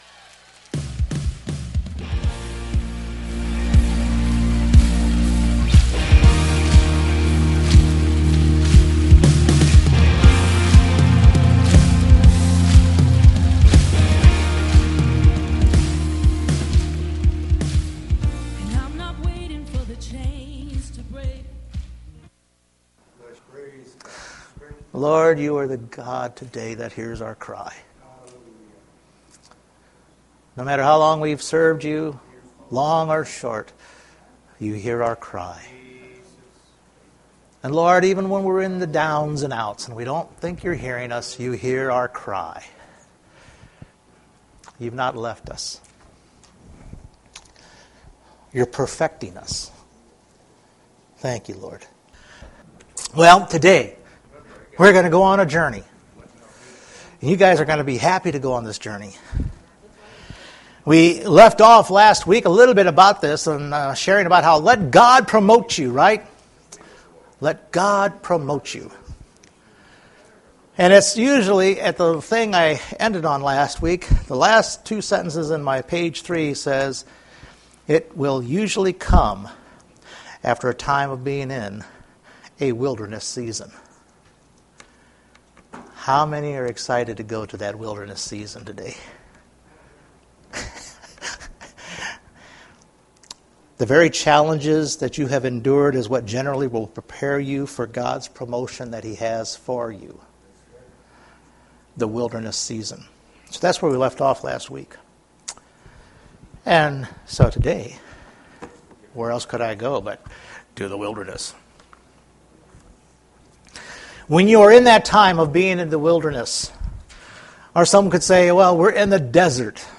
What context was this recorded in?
Numbers 13 Service Type: Sunday Morning When God tells you to enter your promised land trust Him and go!